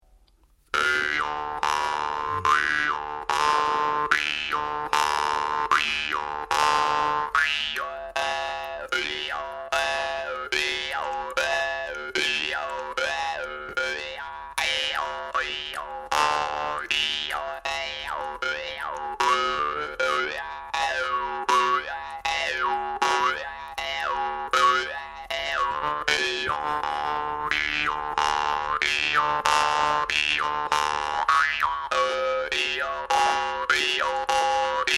instrumental, Saghai people
chatkhan (wooden box zither)